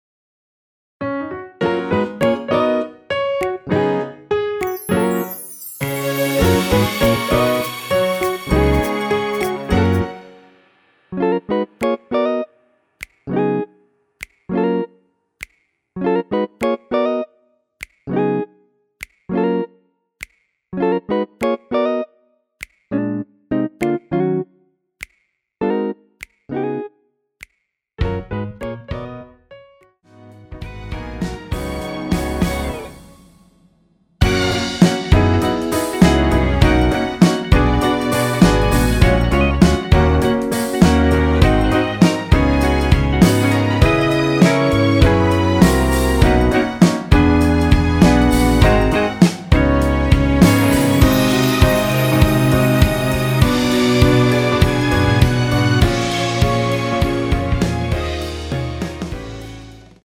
원키에서 (+3)올린 MR 입니다.
F#
앞부분30초, 뒷부분30초씩 편집해서 올려 드리고 있습니다.
중간에 음이 끈어지고 다시 나오는 이유는